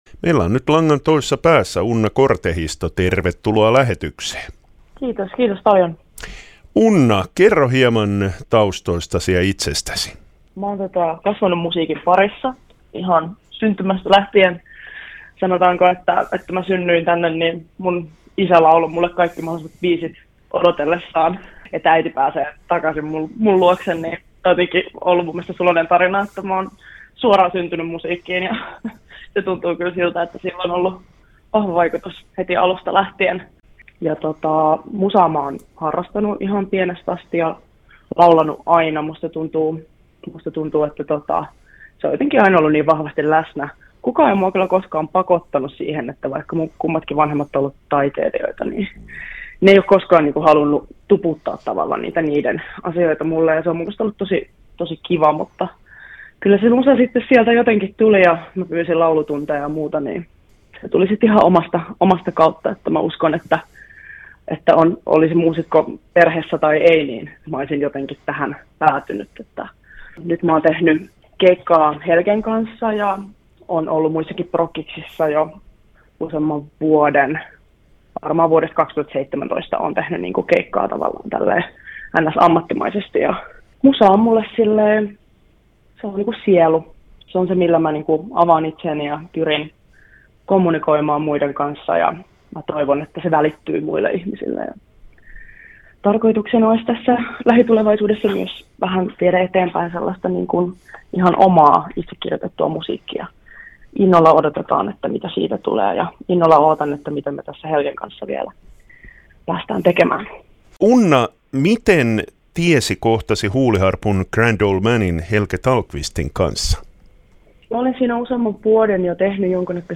haastattelussa